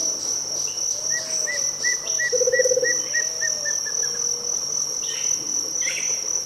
Papa-taoca-do-sul (Pyriglena leucoptera)
Nome em Inglês: White-shouldered Fire-eye
Fase da vida: Adulto
Localidade ou área protegida: El Soberbio
Condição: Selvagem
Certeza: Gravado Vocal
Batara-negro.mp3